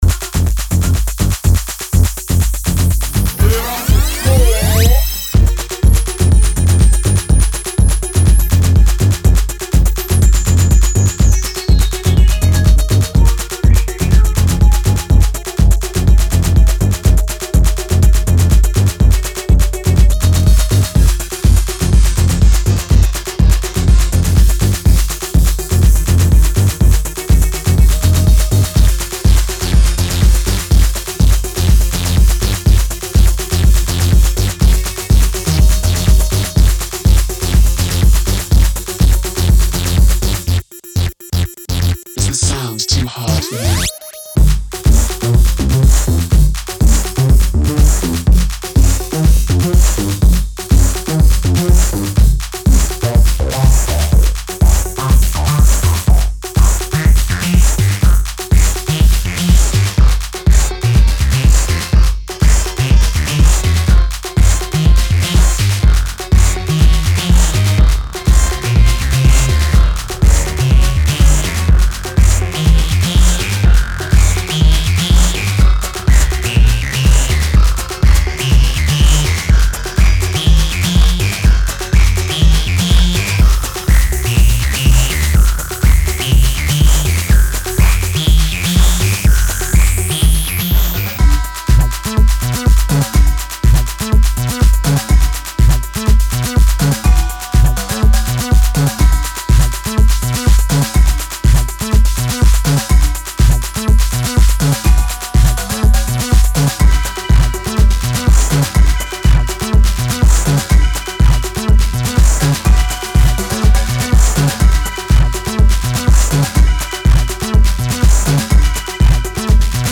シカゴ・ゲットー・ハウスにフレンチ・エレクトロを合体、IDM/グリッチ的エディット感覚も注入された強刺激グルーヴ。